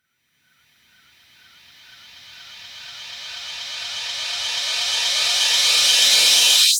VEH1 Reverse - 11.wav